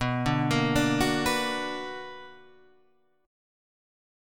B Minor Major 7th